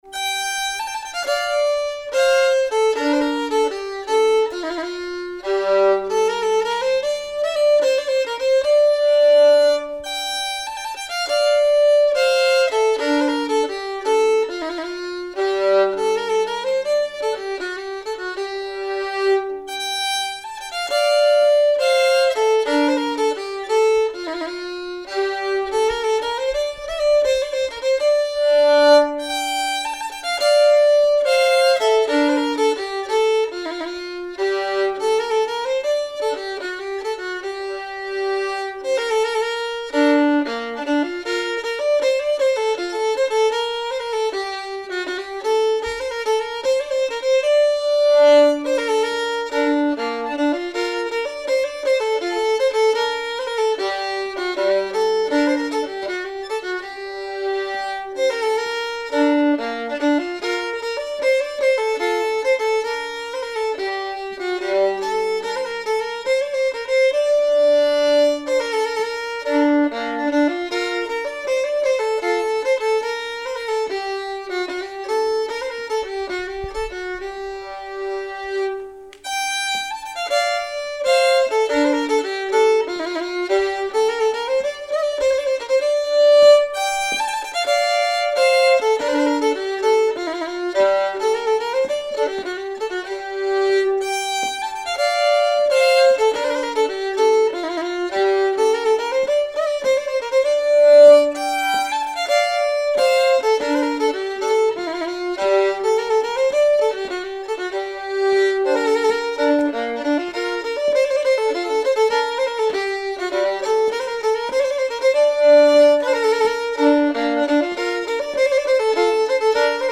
Polska e Blank Anders Orsa inspirerad